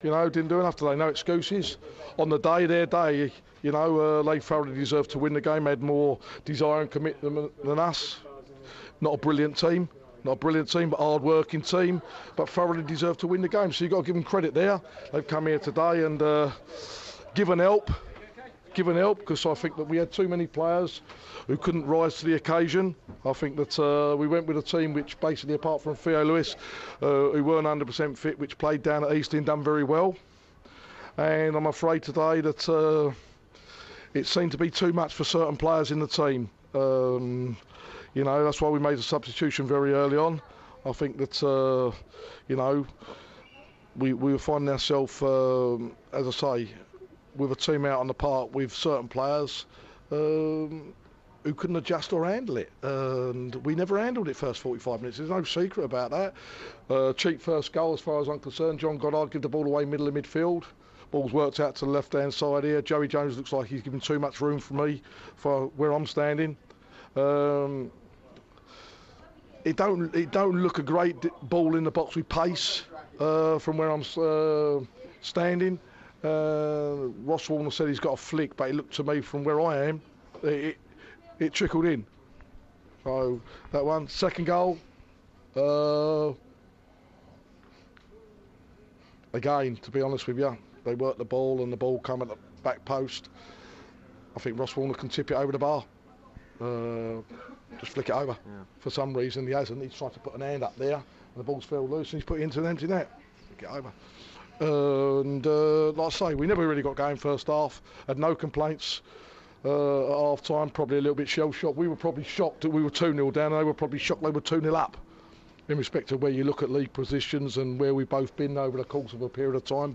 speaks to BBC Surrey after defeat to Aldershot Town